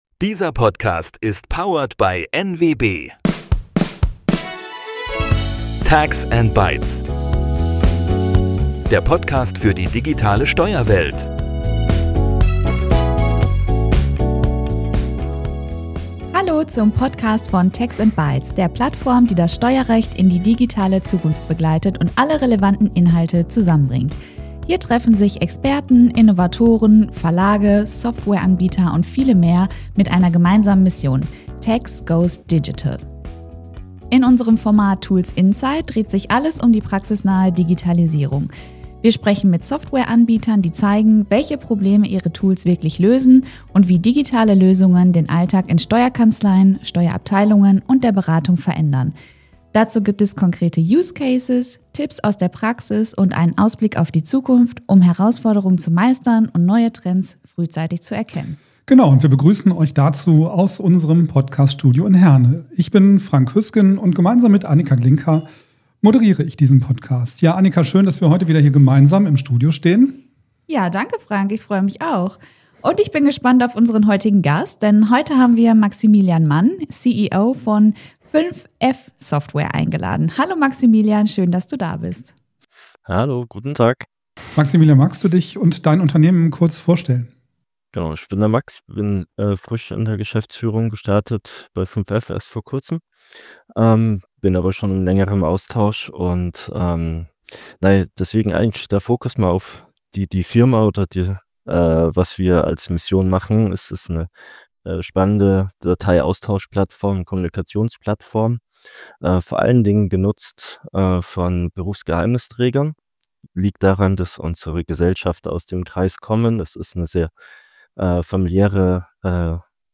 Hört rein und entdeckt, wie ihr eure Kanzlei fit für morgen macht – direkt aus unserem Studio in Herne.